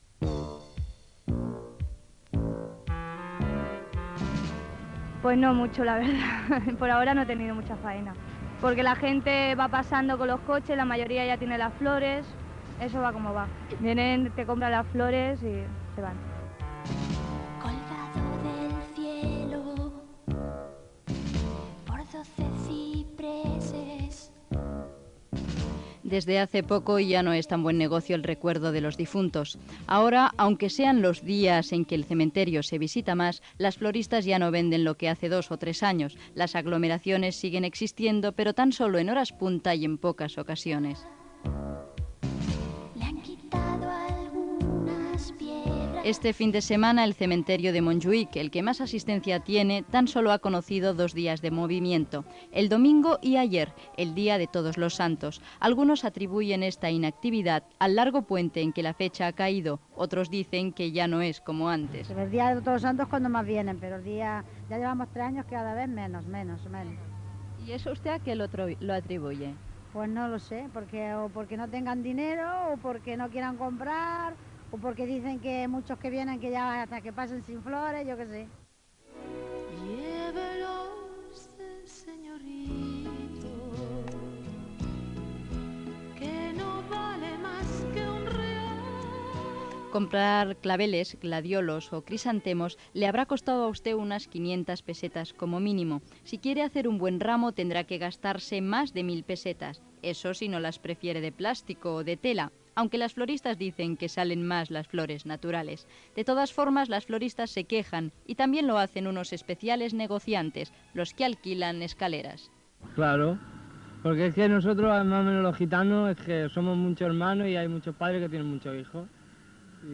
Reportatge sobre el Dia de Tots Sants al Cementiri de Monjuïc de Barcelona
Informatiu